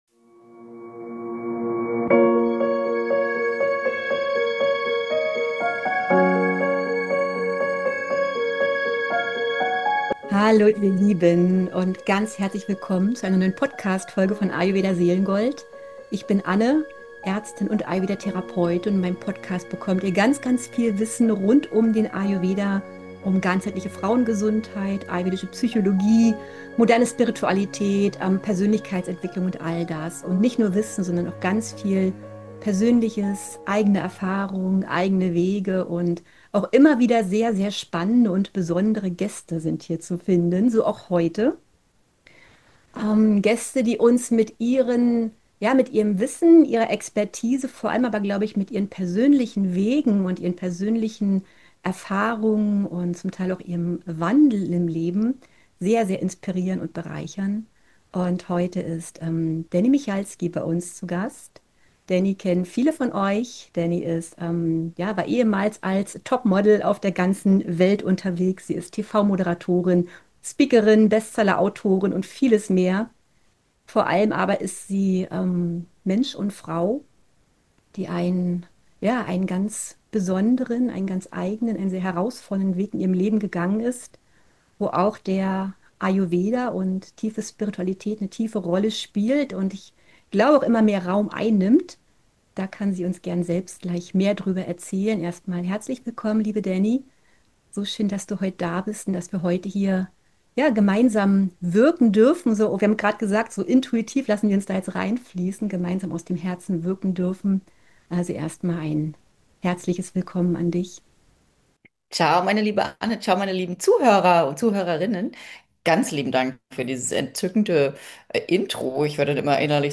Dieses Gespräch ist ehrlich, intensiv und tief berührend.